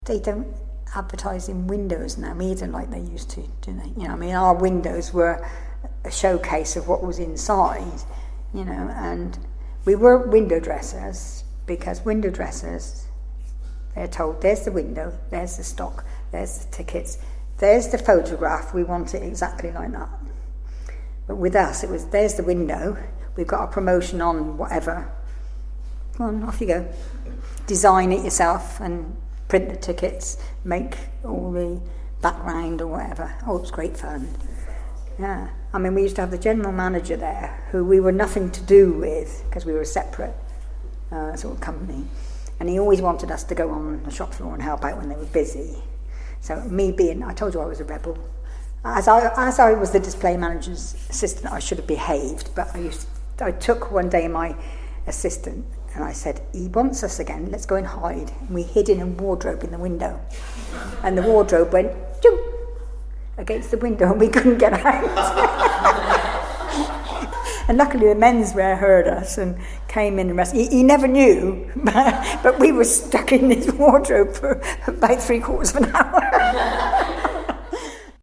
recorded these anecdotes, memories and experiences of Portland people.